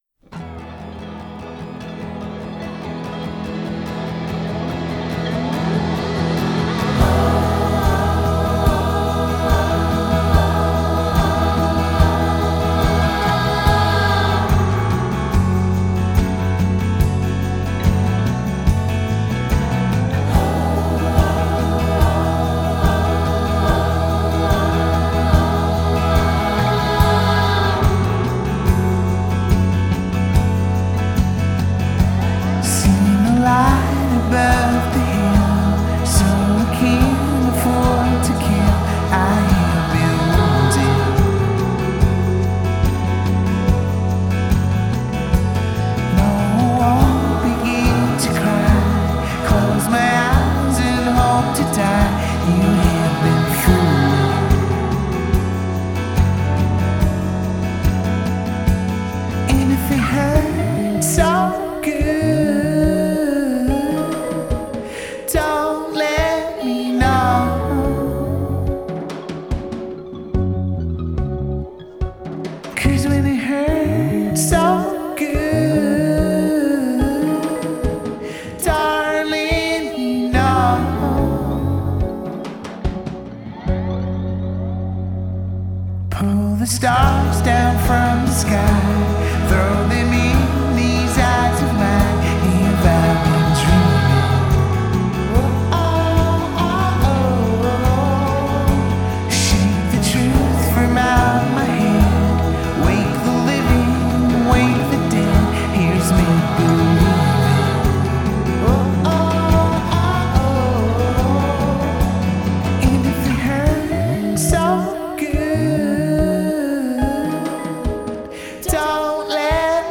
a keening ambient swirl